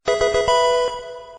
Categoría Notificaciones